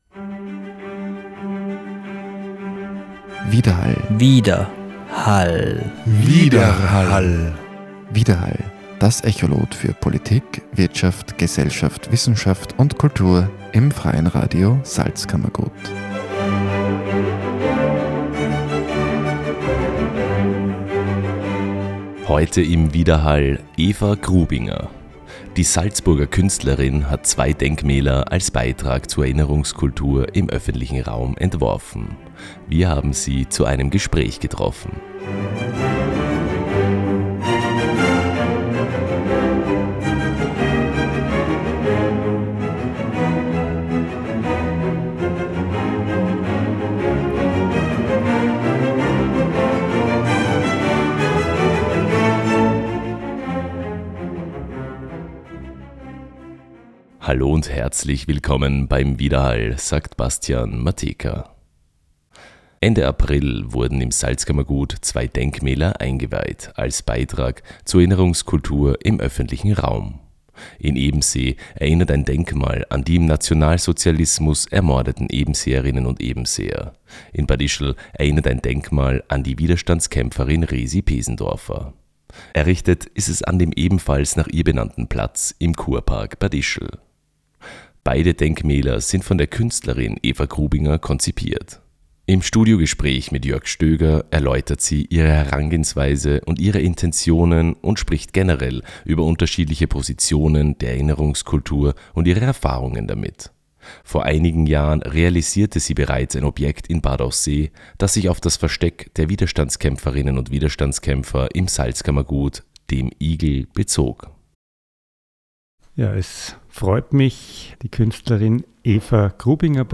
Im Studiogespräch